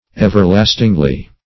everlastingly - definition of everlastingly - synonyms, pronunciation, spelling from Free Dictionary
Everlastingly \Ev`er*last"ing*ly\, adv.